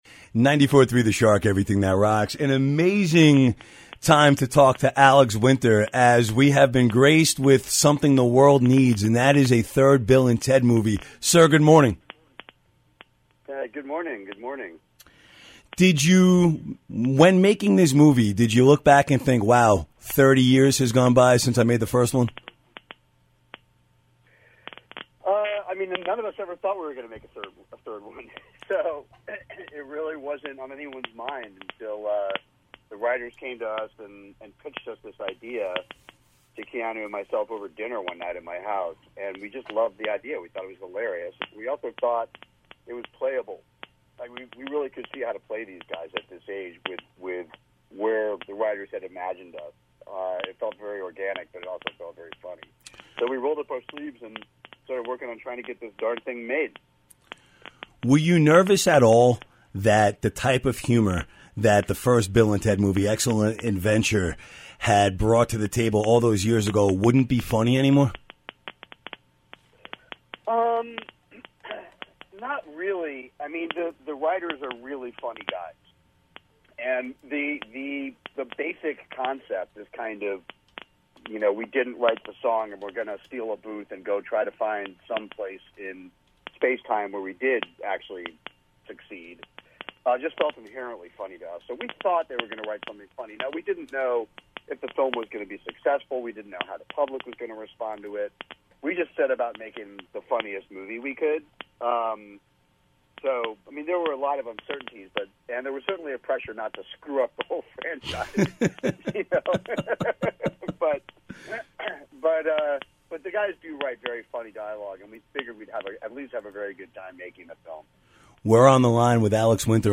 An interview with Alex Winter days before the release of Bill And Ted 3